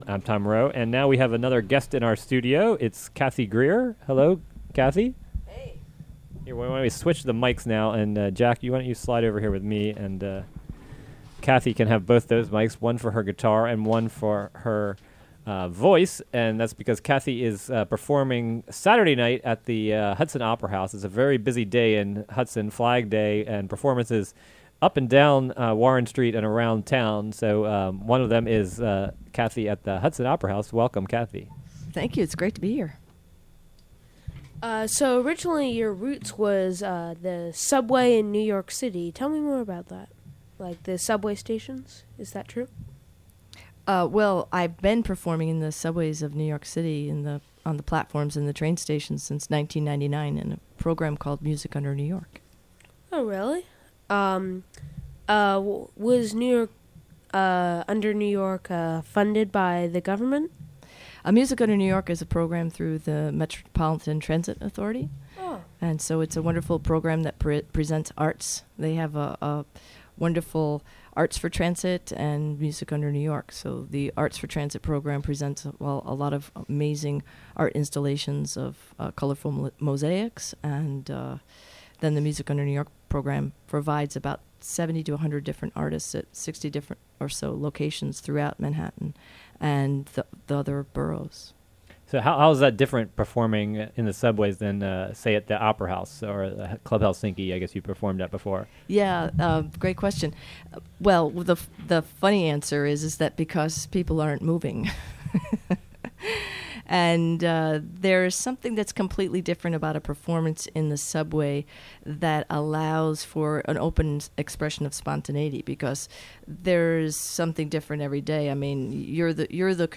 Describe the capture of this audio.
live in-studio performance